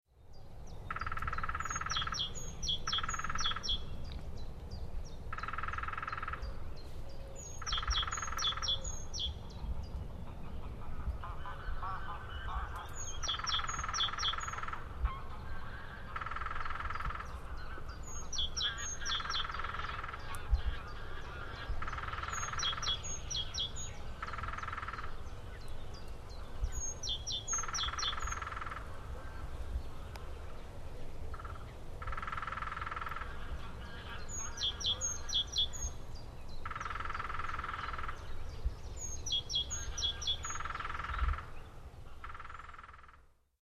Lesser Spotted Woodpecker 6